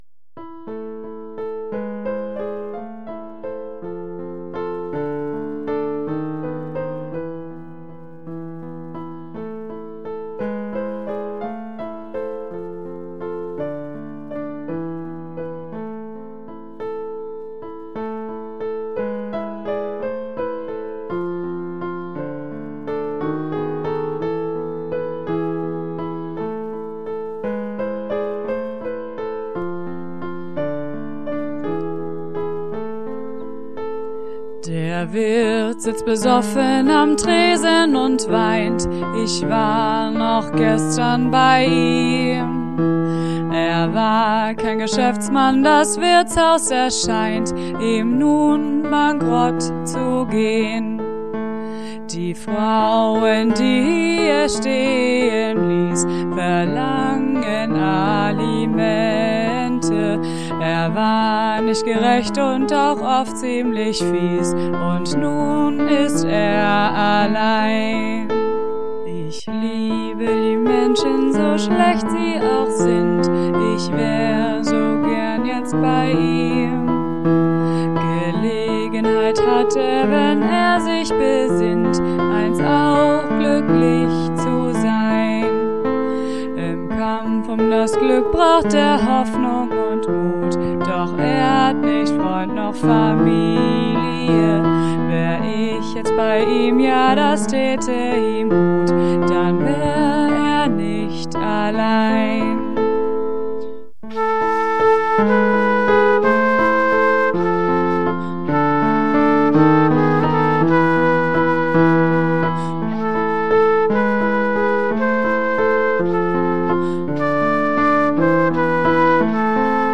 The album gets really philosophical and is concerning topics like death and love and hope from an unusual point of view. About 10 musicians took part in playing instruments and singing.